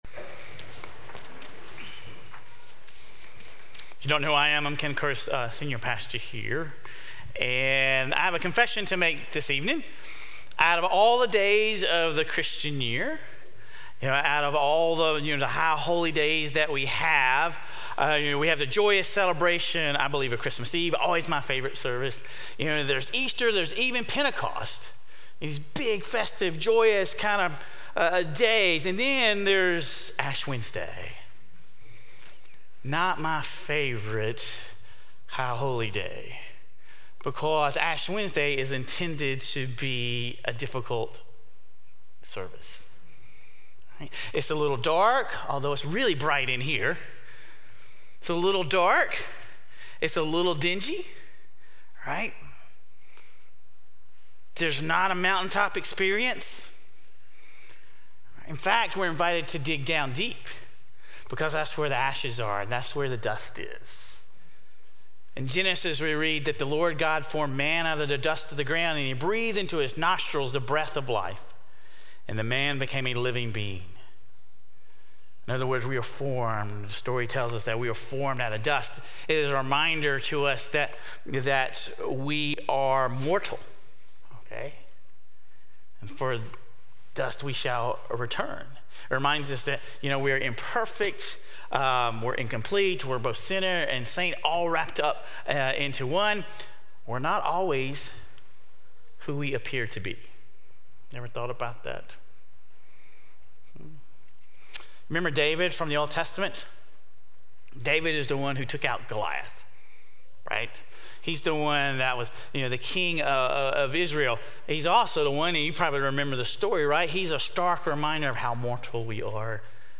St. Stephen commemorated Ash Wednesday and the beginning of Lent on Feb. 14, 2024.
Sermon Reflections: What resonated most with you from the sermon?